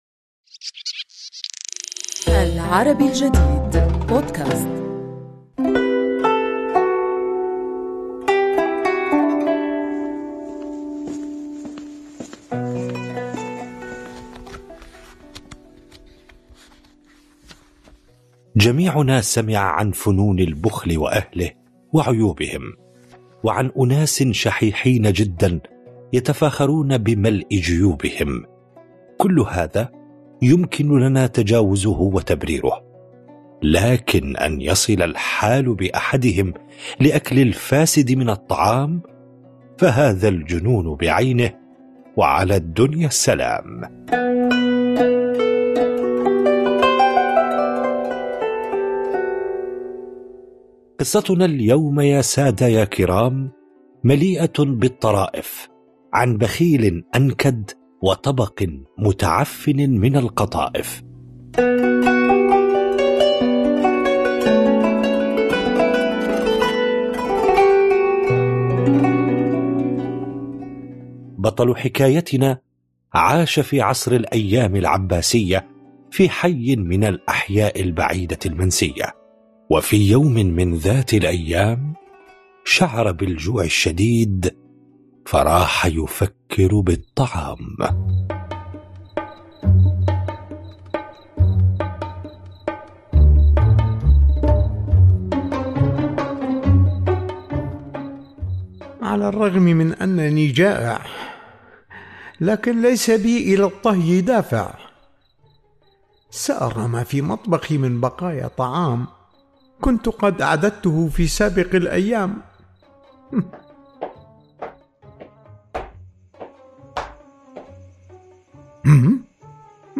نروي لكم اليوم في بودكاست "حكواتي" حكاية مليئة بالطّرائف، عن رجل بخيل وطبق متعفّن من القطائف.